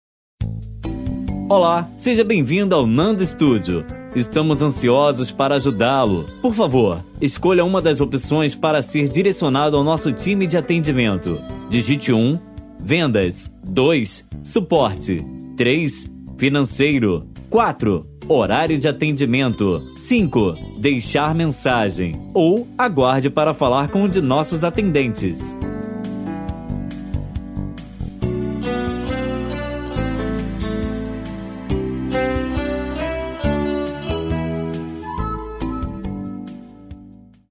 Atendimento URA
Todos os áudios são produzidos e renderizados na mais alta qualidade e convertidos para o formato que melhor atender suas necessidades.